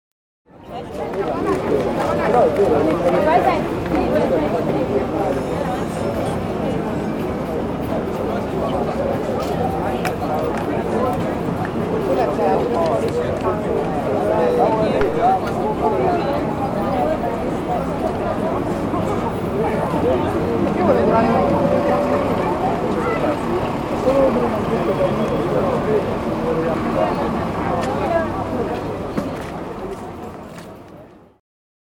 groep3_les1-2-5_omgevingsgeluid3.mp3